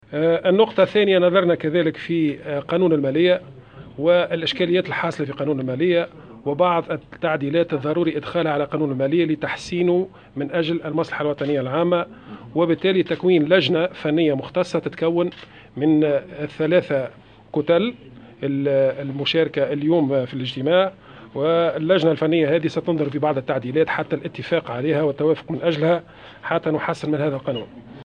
وسيتم تشكيل لجنة فنية مختصة تضم أعضاء من كتل الأحزاب المذكورة، لإدخال التعديلات الضرورية، وفق تصريح الناطق الرسمي باسم حزب نداء تونس منجي الحرباوي لمراسلة الجوهرة اف ام اثر انتهاء الاجتماع.